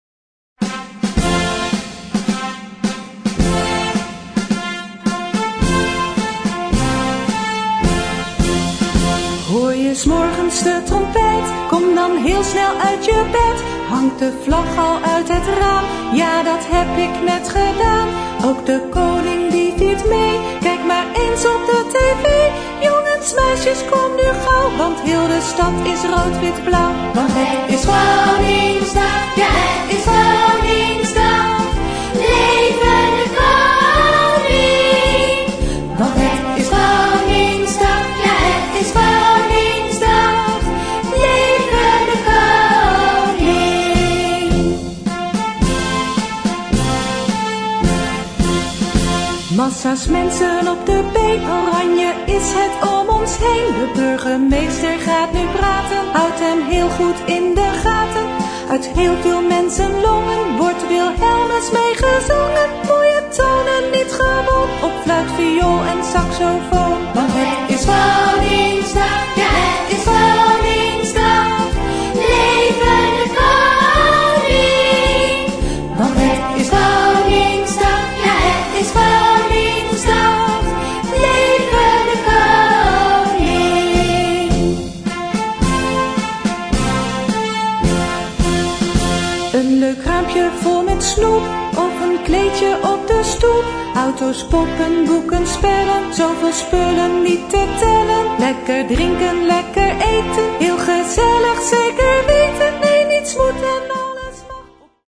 Partitions pour ensemble flexible, 4-voix + percussion.